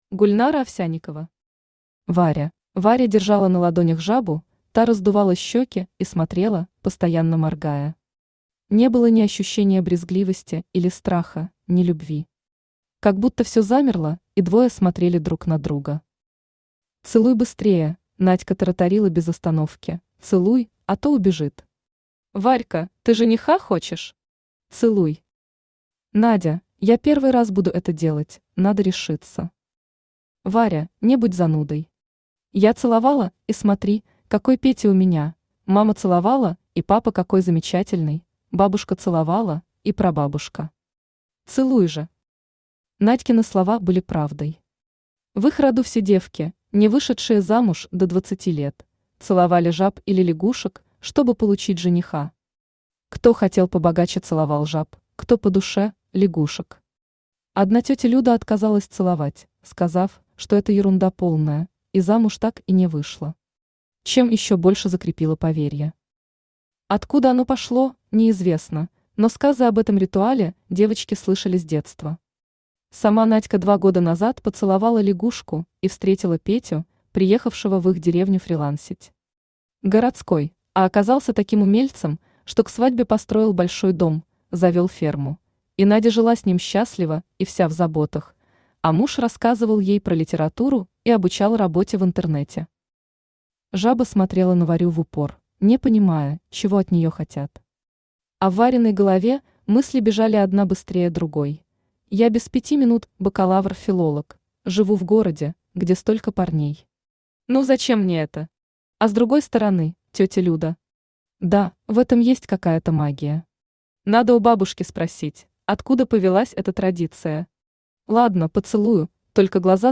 Aудиокнига Варя Автор Гульнара Овсяникова Читает аудиокнигу Авточтец ЛитРес.